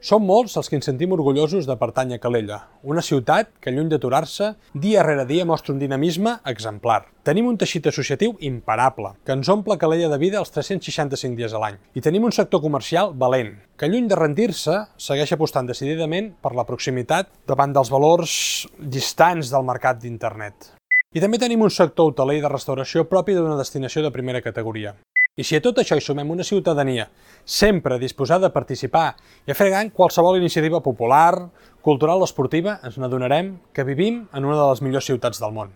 En el seu missatge institucional de Nadal, l’alcalde Marc Buch ha posat l’accent en l’orgull de pertinença a Calella i en el dinamisme que, assegura, defineix la ciutat.